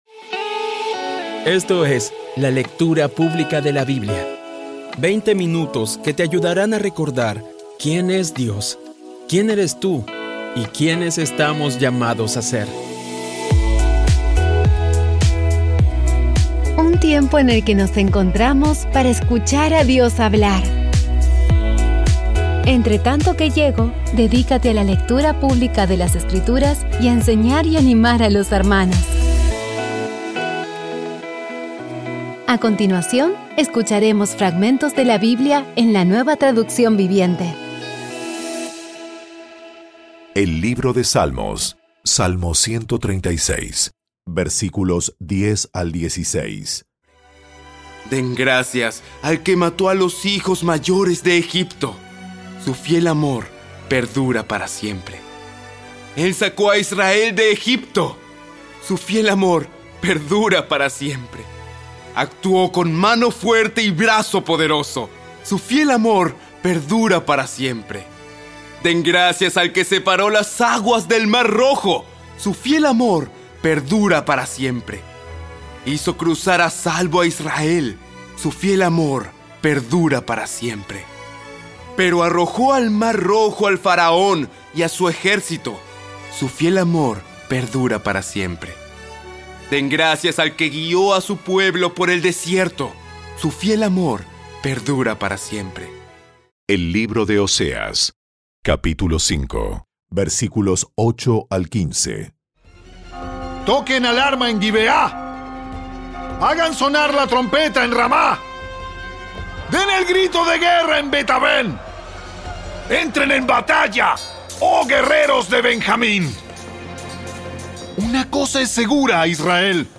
Audio Biblia Dramatizada Episodio 338
Poco a poco y con las maravillosas voces actuadas de los protagonistas vas degustando las palabras de esa guía que Dios nos dio.